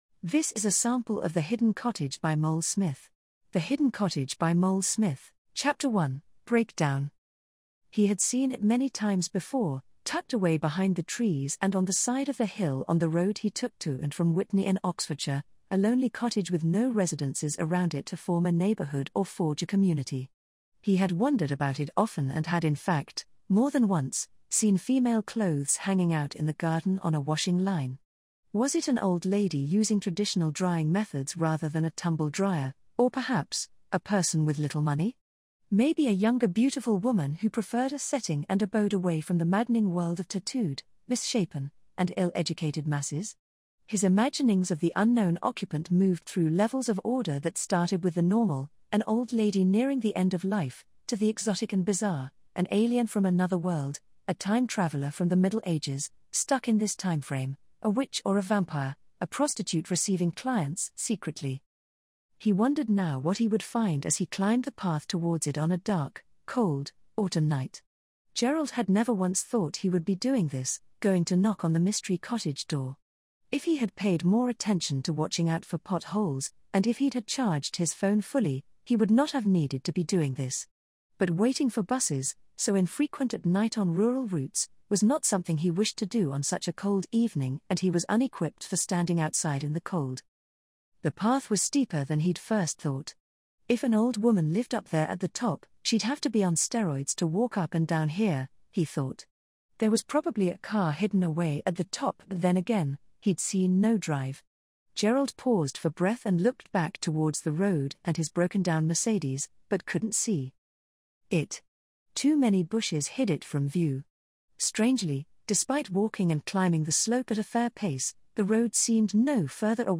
THE HIDDEN COTTAGE AUDIO BOOK PLAY SAMPLE FIRST